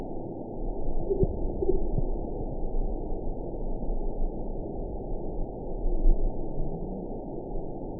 event 922560 date 01/29/25 time 22:02:05 GMT (10 months ago) score 7.85 location TSS-AB10 detected by nrw target species NRW annotations +NRW Spectrogram: Frequency (kHz) vs. Time (s) audio not available .wav